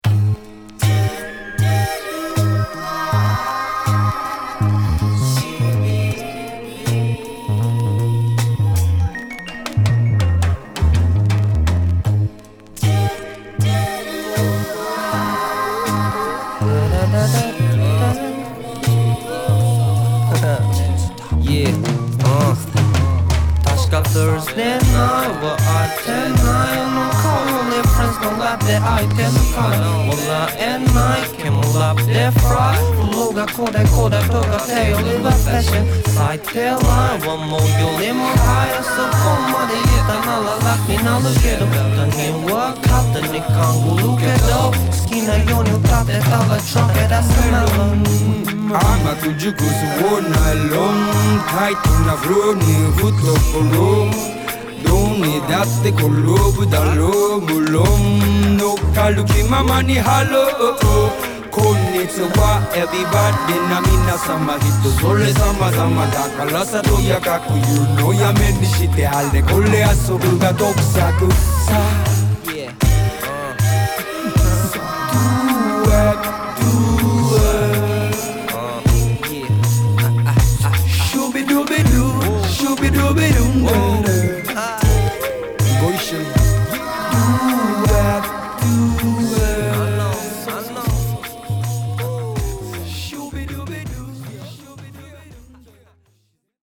・ HIP HOP JAPANESE 12' & LP